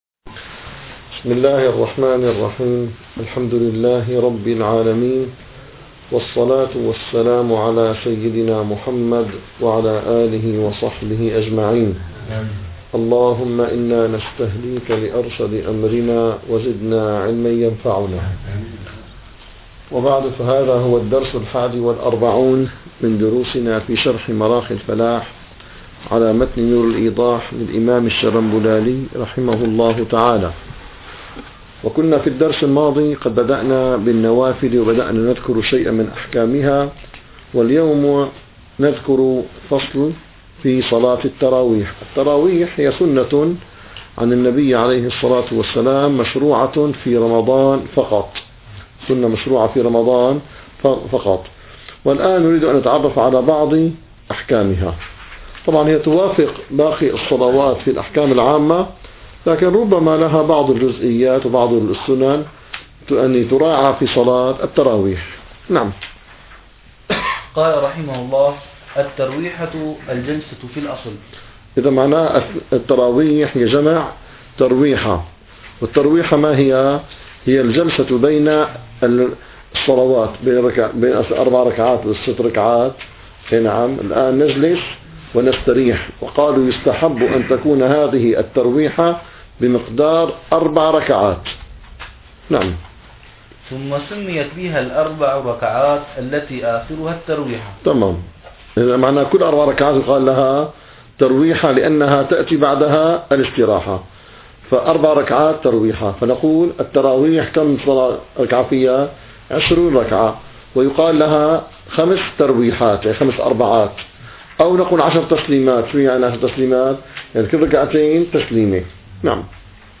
- الدروس العلمية - الفقه الحنفي - مراقي الفلاح - 41- صلاة التراويح